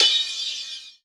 D2 RIDE-05.wav